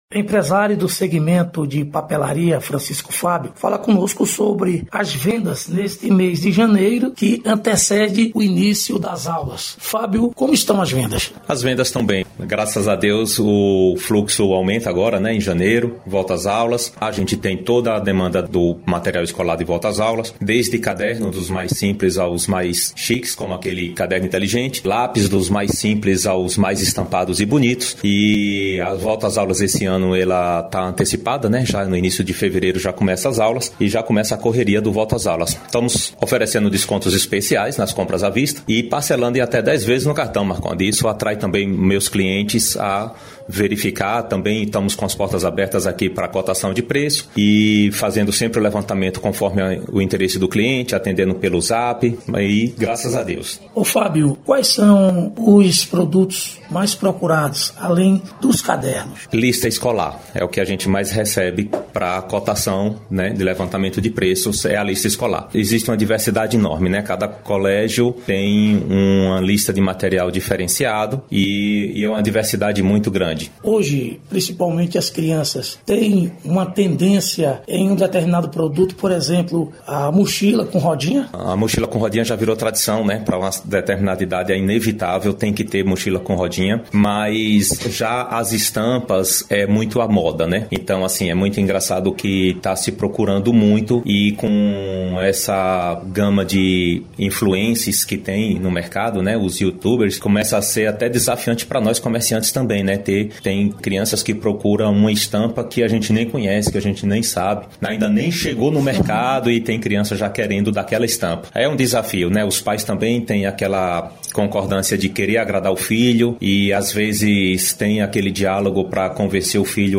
Reportagem: Comerciantes de Campo Formoso – Expectativas de vendas na volta as aulas